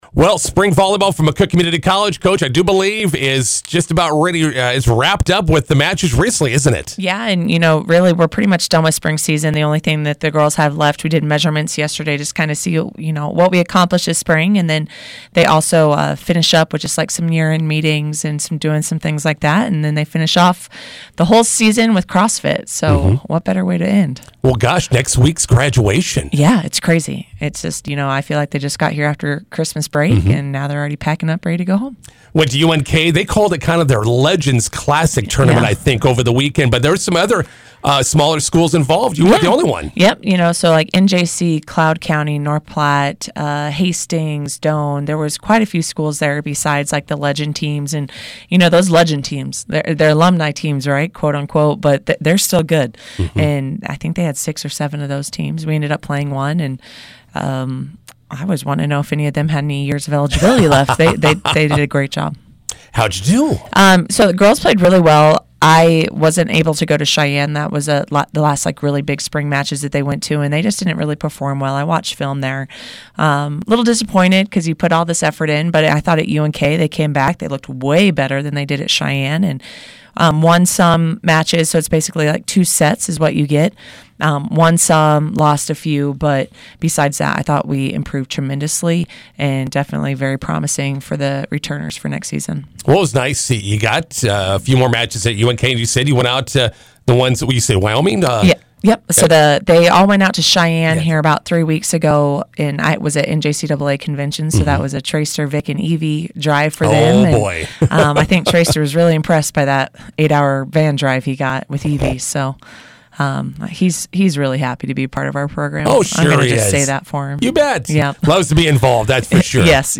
INTERVIEW: MCC volleyball wraps up spring season with matches at UNK Legends Classic.